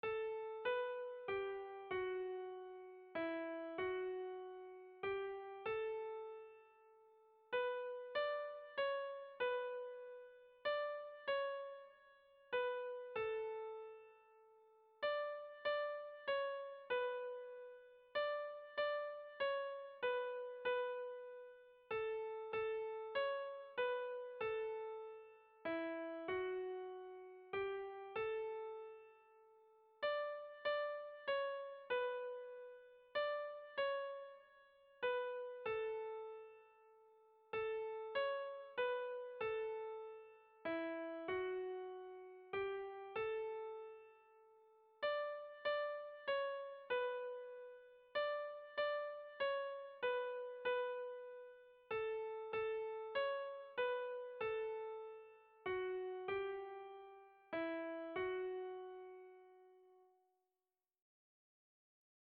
Irrizkoa
Kopla handia
ABD